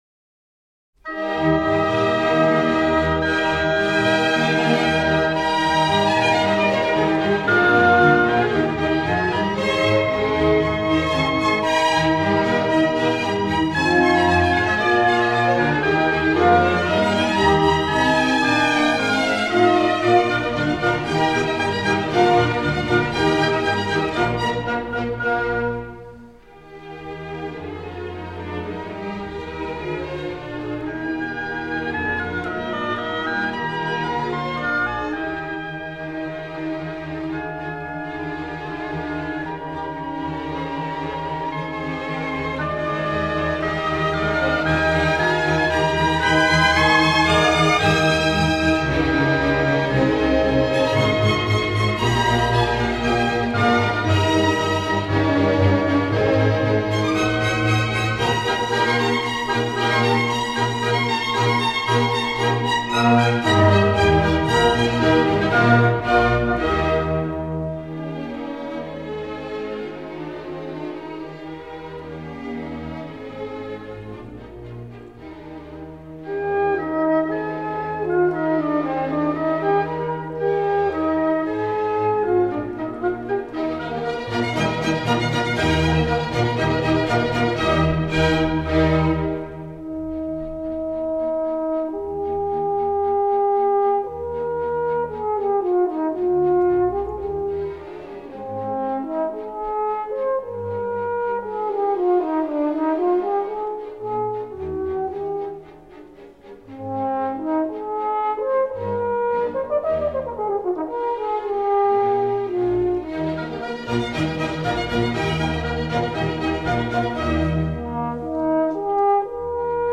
F05-09 Horn Concerto No. 4 in E flat maj | Miles Christi